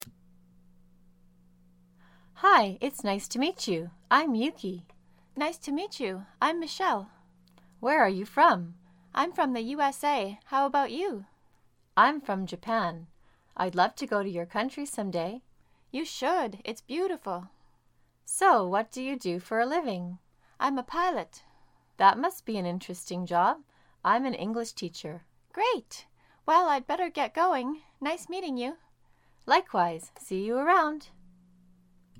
Dialogue - Introductions